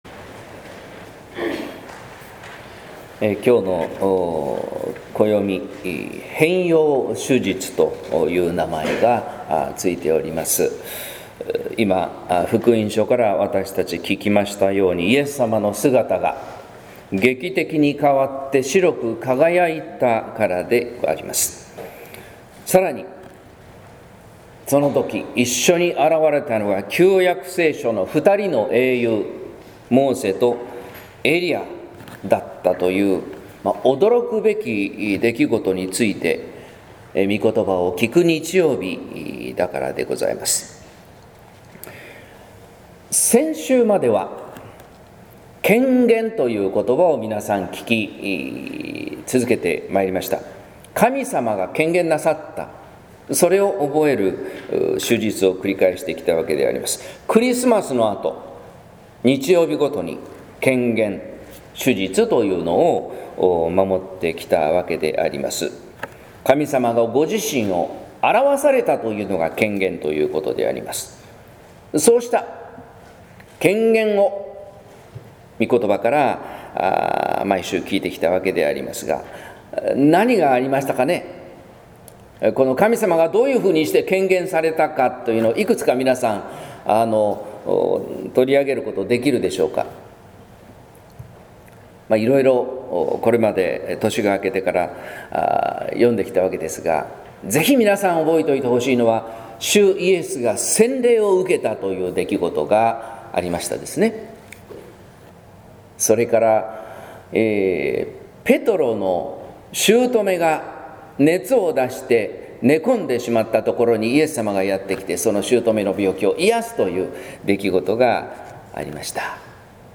説教「栄光から十字架へ」（音声版）